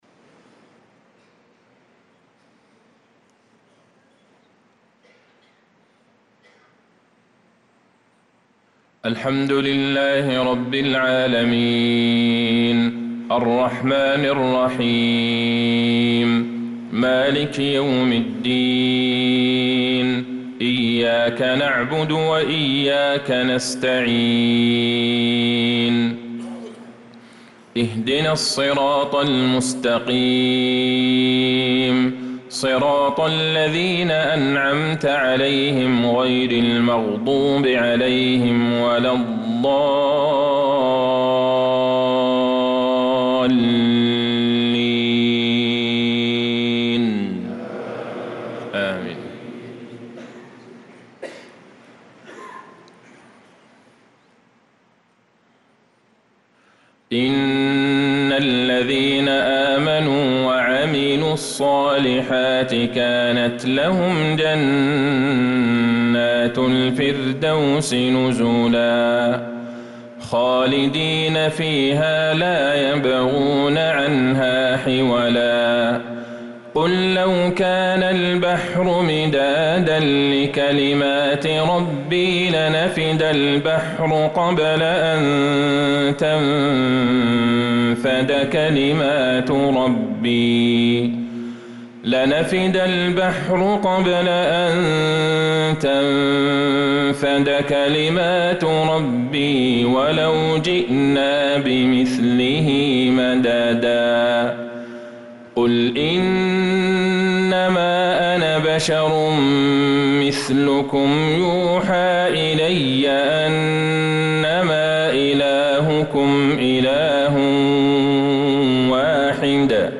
صلاة المغرب للقارئ عبدالله البعيجان 20 ذو الحجة 1445 هـ